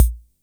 Index of /musicradar/essential-drumkit-samples/Vintage Drumbox Kit
Vintage Kick 03.wav